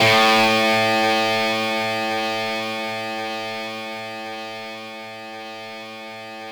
NoteA2.wav